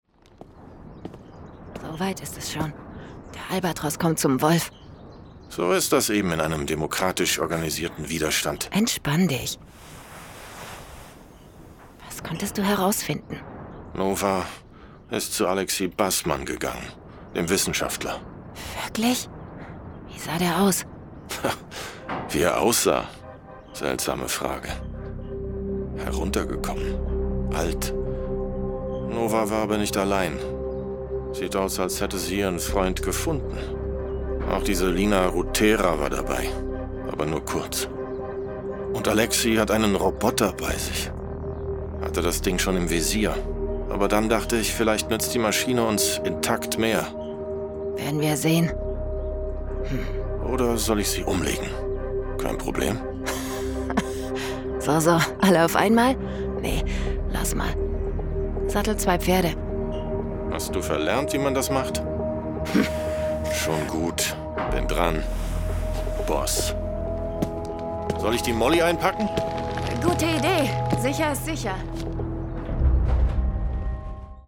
FEMALE GERMAN VOICE ARTIST and Voice Actress
My personal recording studio allows your audio file to be recorded very easily and guarantees the highest audio quality.